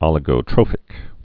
(ŏlĭ-gō-trōfĭk, -trŏfĭk, ōlĭ-)